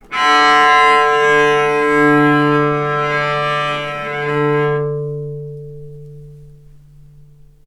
vc_sp-D3-mf.AIF